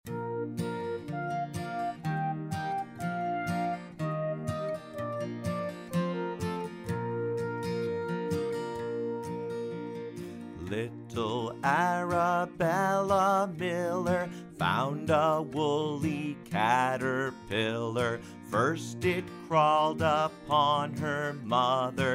Song in MP3 Format